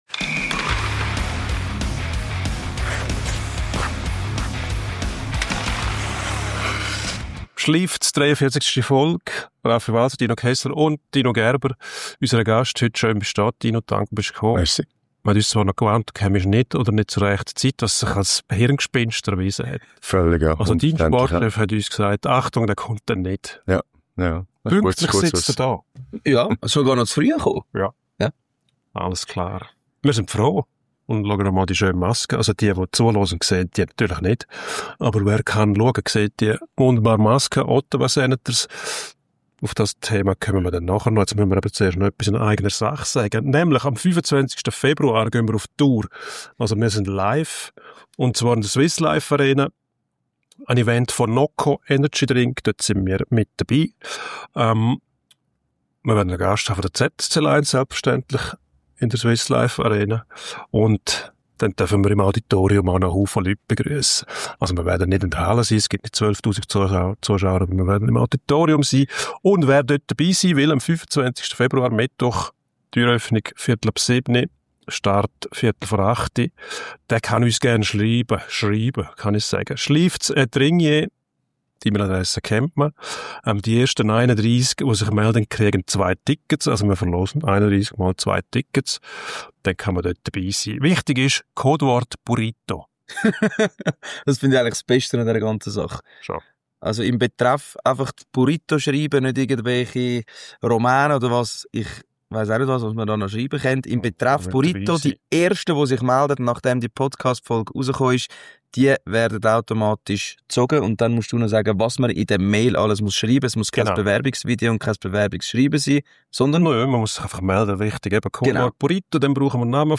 im Studio. Der erzählt, wie sich Hockey in Russland anfühlte und wie man den Stanley Cup gewinnt. Und wie war das 2006 beim Sieg gegen Kanada in Turin?